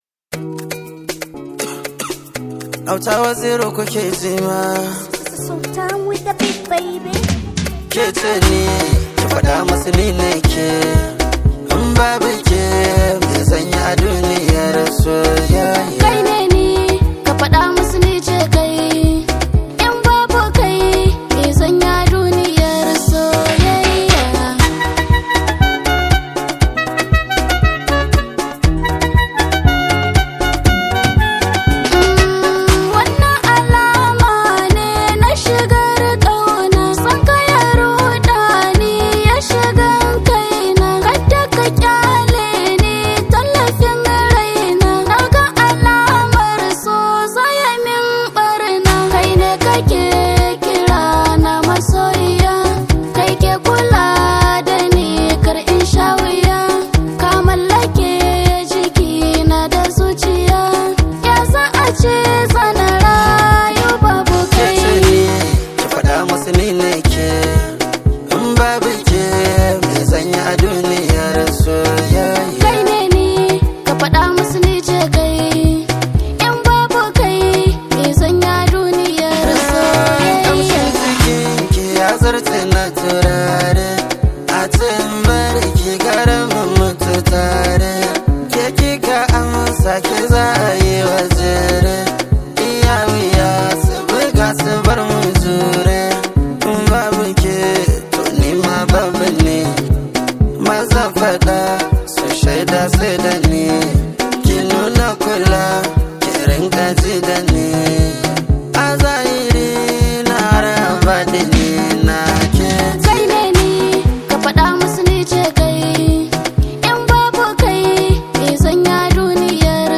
Hausa Songs
wakarsa ta soyayya mai ratsaya zuciya